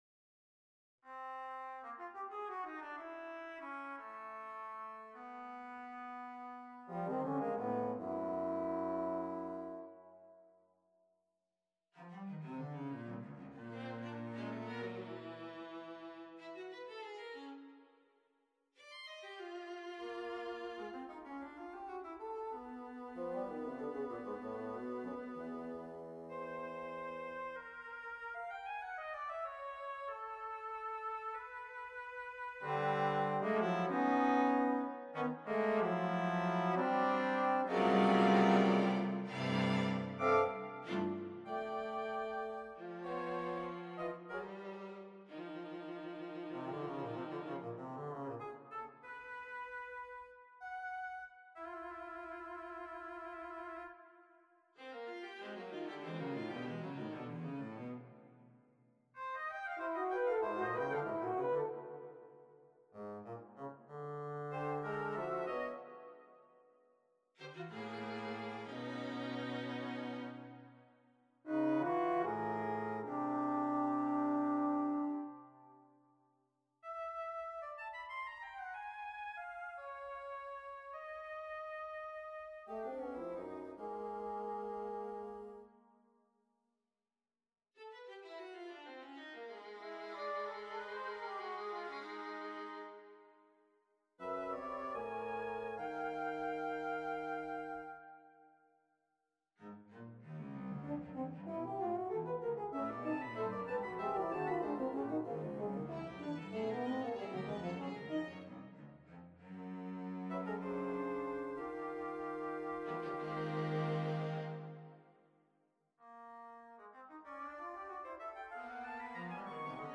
Nonet for Winds, Brass, and Strings on a purpose-selected tone row Op.67 1. Andante con moto - Poco pi� mosso - Tempo primo 2.
Allegro molto - Pi� mosso - Presto - Tempo primo con meno mosso 4.
Presto energico - Prestissimo - Tempo primo Date Duration Download 1 October 2017 26'25" Realization (.MP3) Score (.PDF) 36.2 MB 2.14 MB